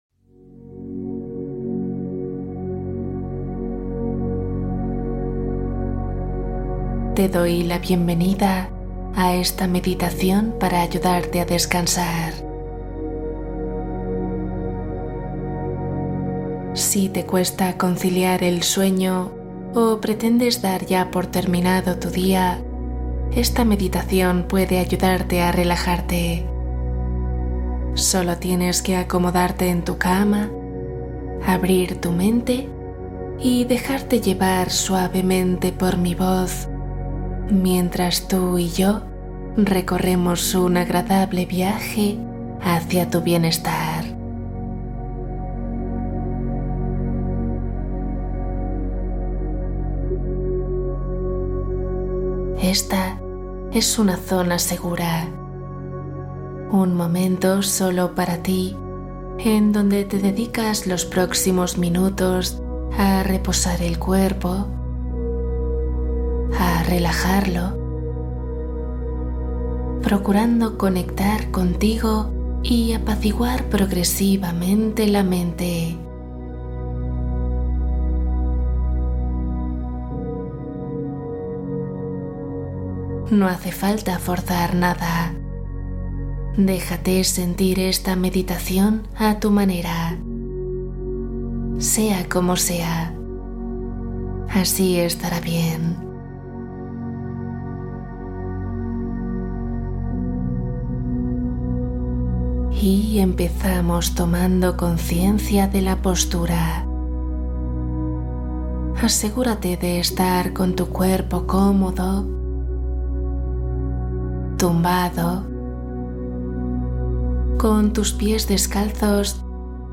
Relajación profunda Meditación para calmar ansiedad y mente